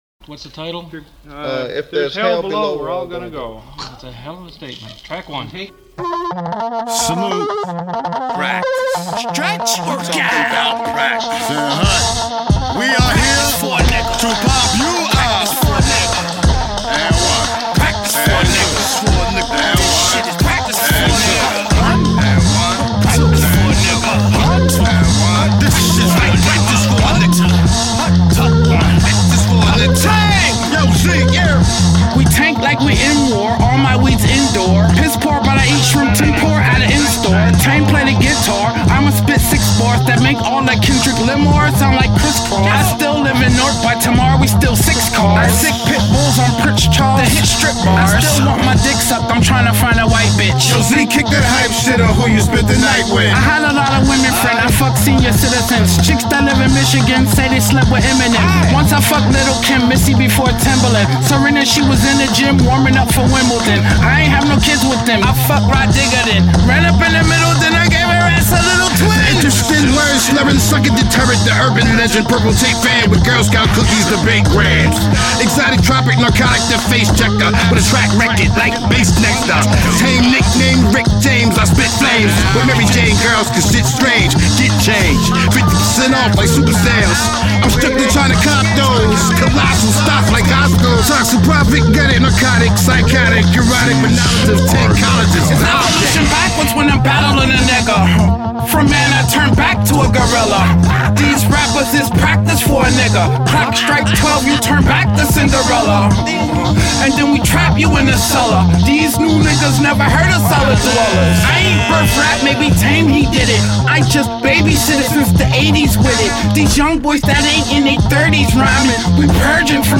shoegaze dream pop band
rock n roll punk
smooth like suede hip hop